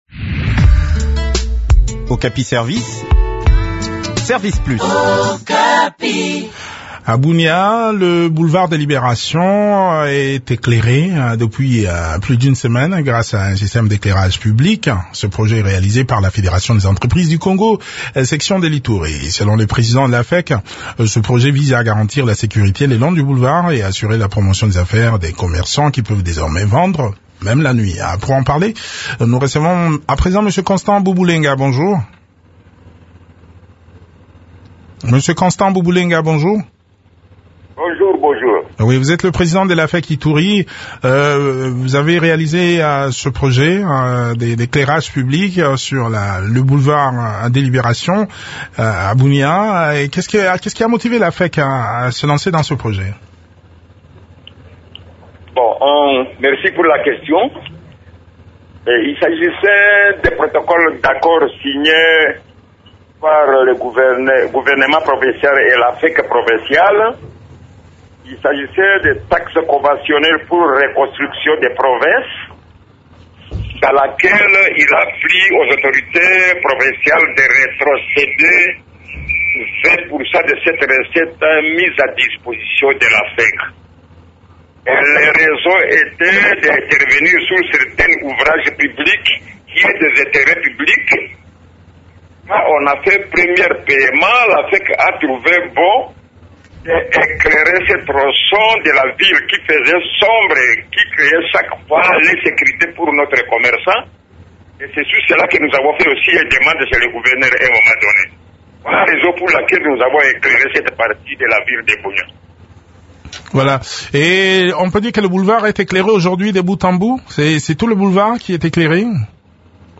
Le point de la situation sur terrain dans cet entretien